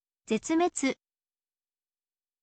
zetsumetsu